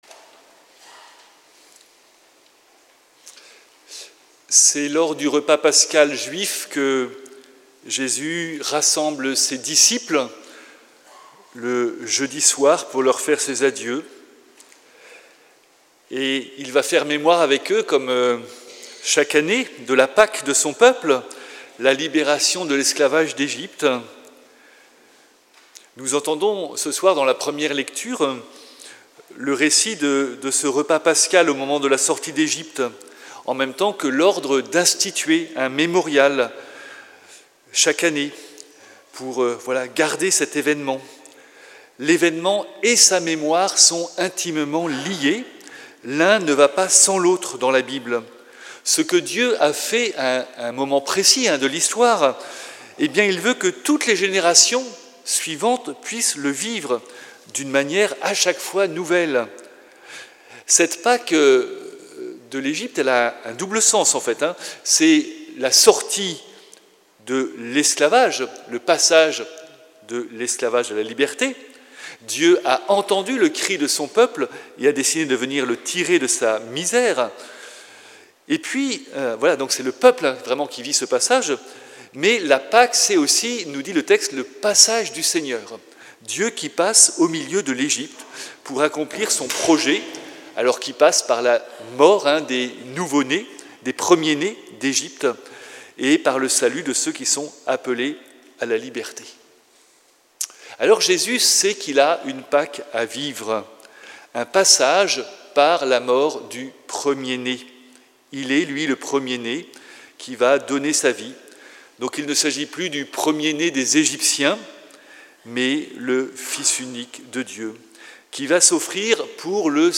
Audio : Homélie du jeudi saint 2 avril 2026